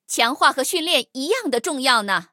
黑豹强化语音.OGG